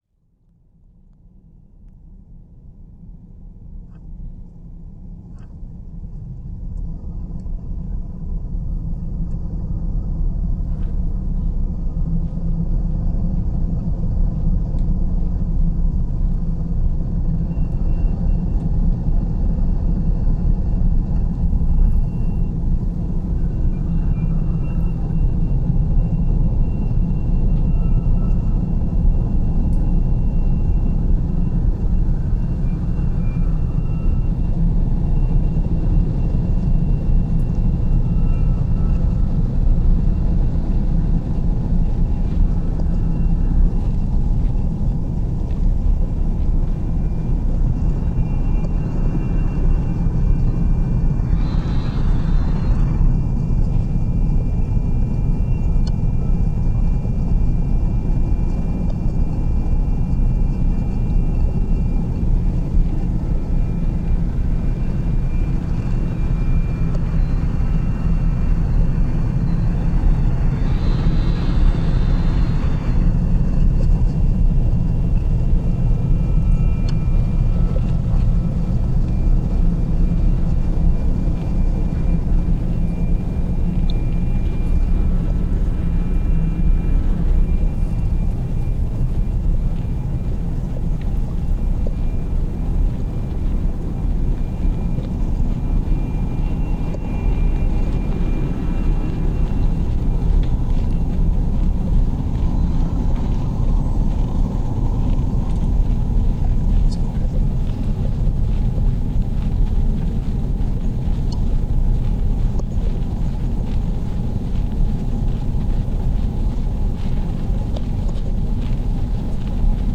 • Instrumentation: experimental electronica